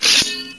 knife_deploy1_hunter.wav